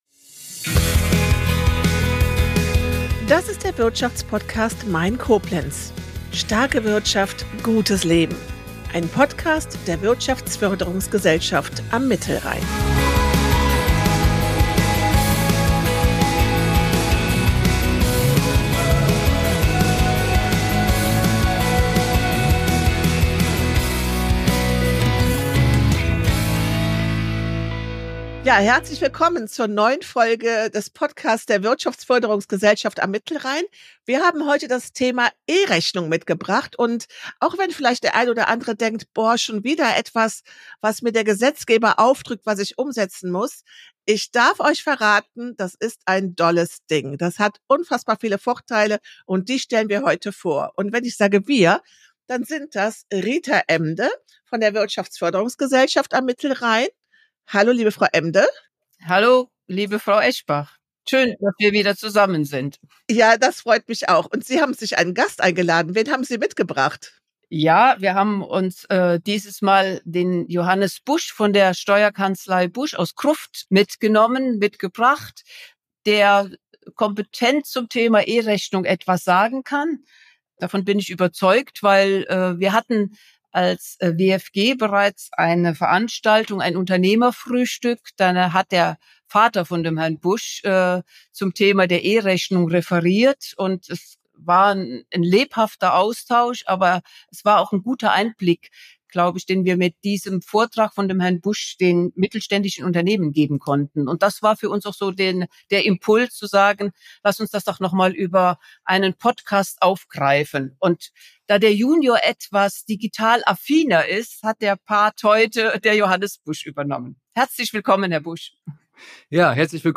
Was sich hinter dem Podcasttitel im Einzelnen verbirgt und mit welchen zukunftsgerichteten Projekten und Angeboten die Wirtschaftsförderungsgesellschaft am Mittelrhein (WFG) vorangeht und Unternehmen unterstützt, darum wird es einmal im Monat in den Gesprächen mit interessanten Gästen, Unternehmen, Partnern gehen.